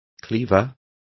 Complete with pronunciation of the translation of cleaver.